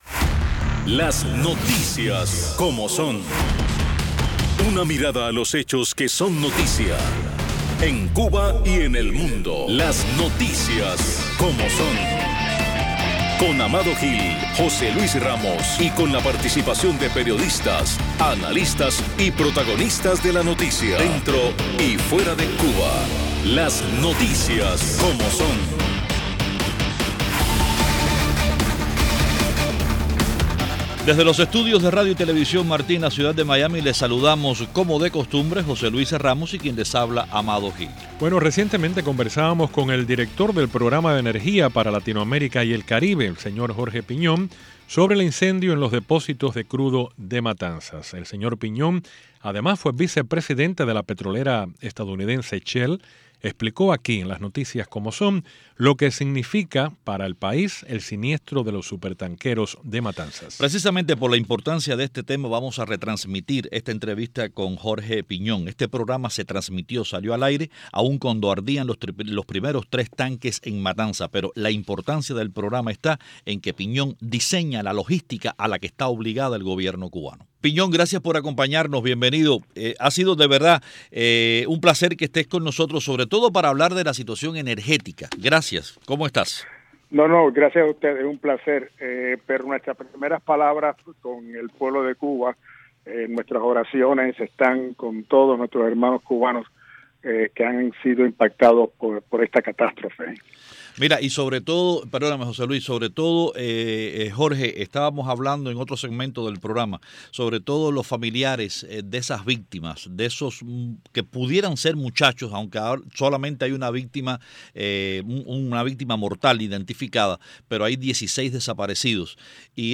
retransmitimos la entrevista